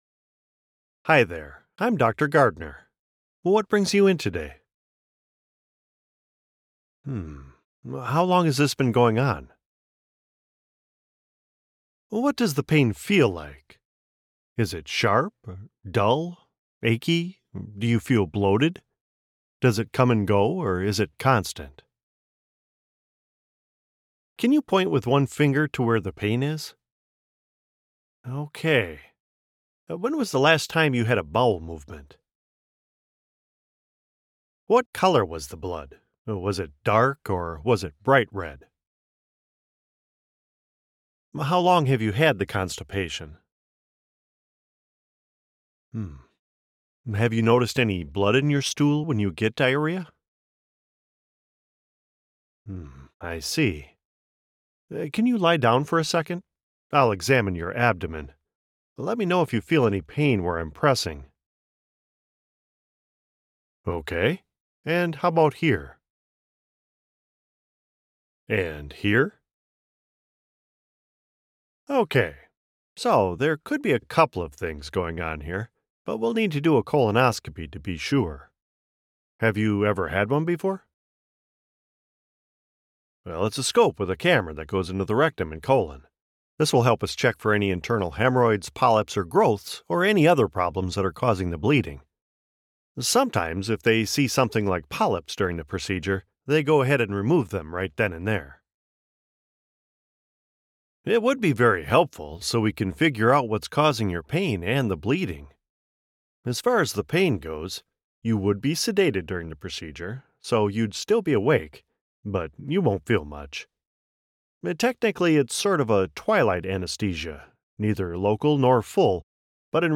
Older Sound (50+)
My voice is a rich baritone, a bit gravelly now that I'm in my mid-50s but still nice and strong, dignified but with lots of energy and expression, that is very well-suited to narrative deliveries for projects such as audiobooks, documentaries, explainers, and suitable broadcast-style advertisements.
E-Learning
Words that describe my voice are baritone, gravelly, expressive.